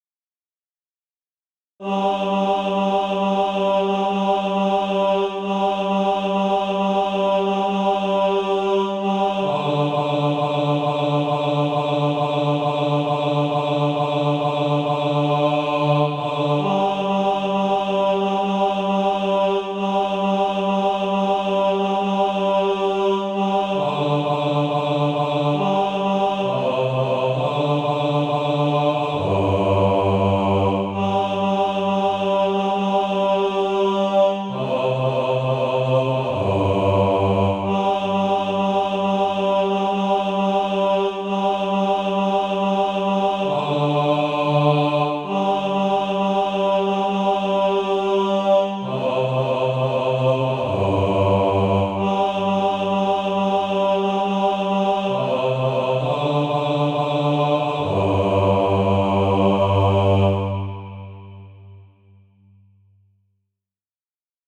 (SATB) Author
Bass Track.
Practice then with the Chord quietly in the background.